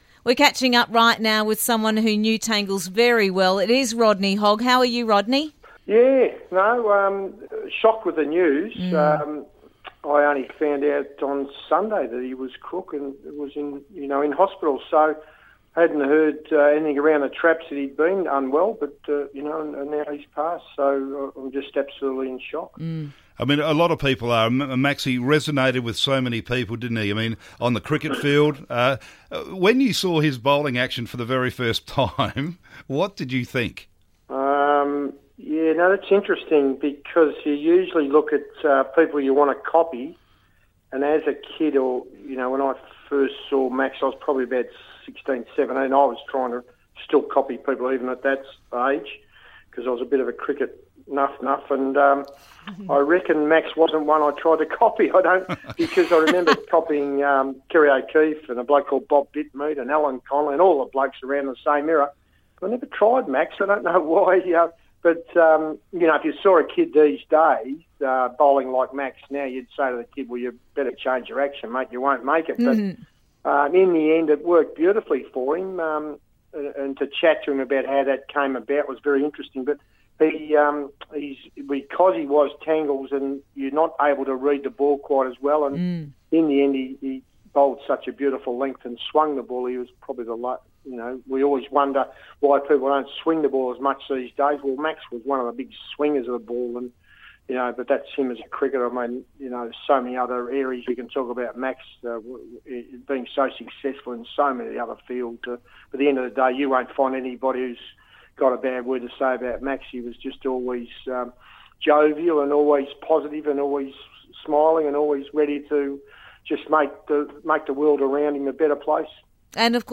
Interview - Rodney Hogg